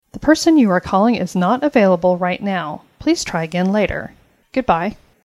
caller unavailable message.